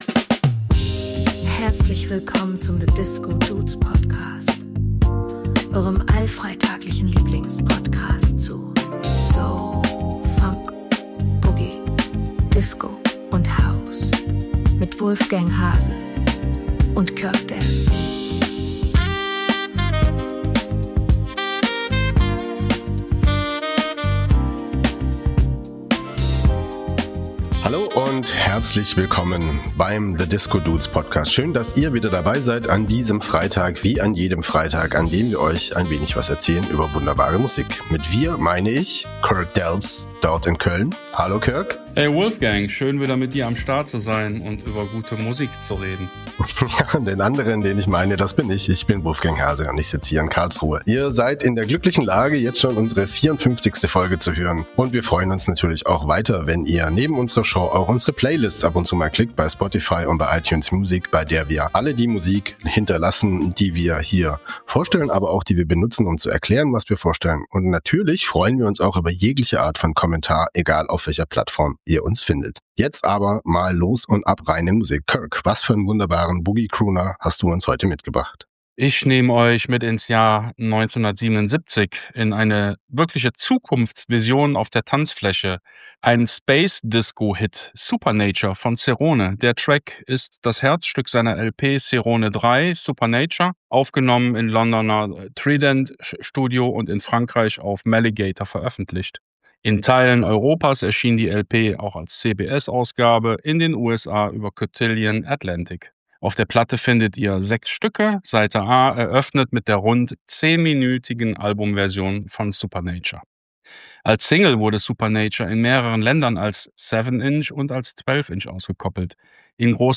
Space Disco meets pure Soulfulness.